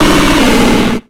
Cri de Cerfrousse dans Pokémon X et Y.